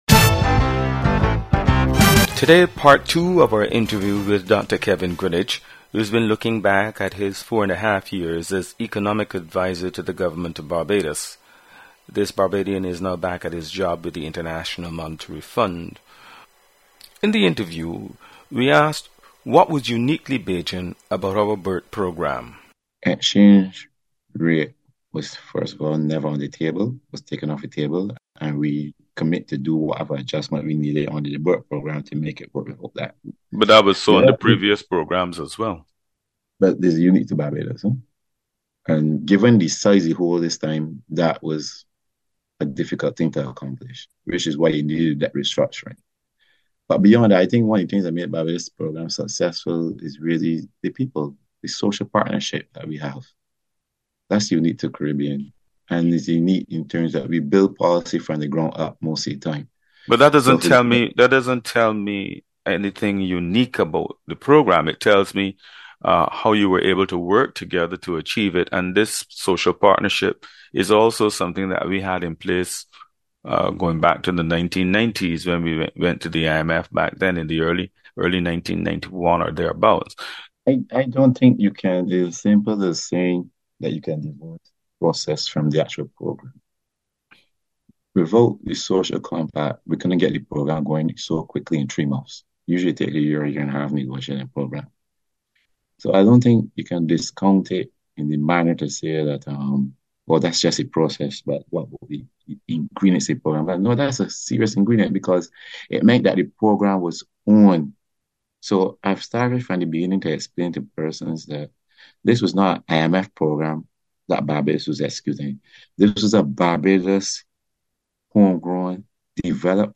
Today part two of our interview